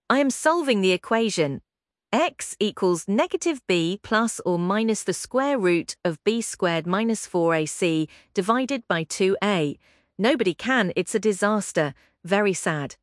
Qwen 3 TTS - Text to Speech [1.7B] Text to Speech
Bring speech to your texts using Qwen3-TTS Custom-Voice model with pre-trained voices or use your custom voice with Qwen3-TTS Clone Voice model
tts_out.mp3